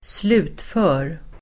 Uttal: [²sl'u:tfö:r]